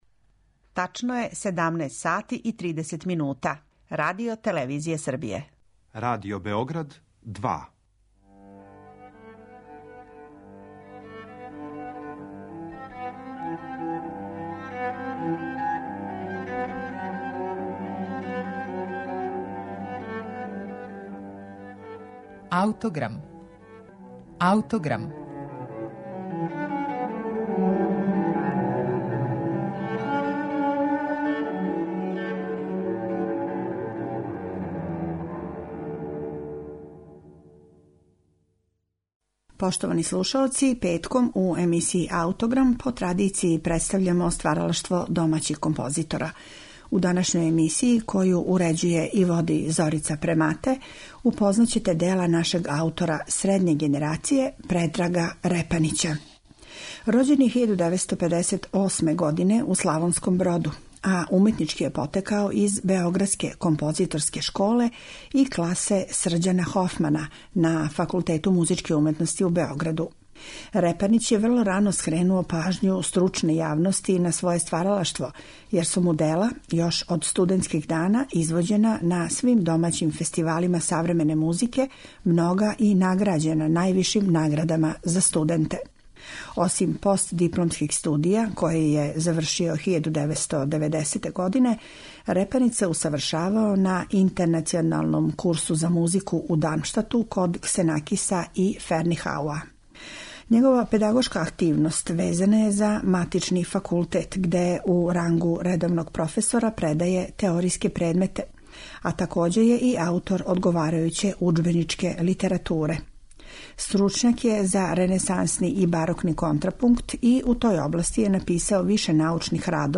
за гудачки оркестар.